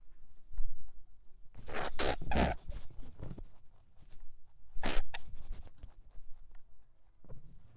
Descarga de Sonidos mp3 Gratis: conejo.
descargar sonido mp3 conejo
conejo-2-.mp3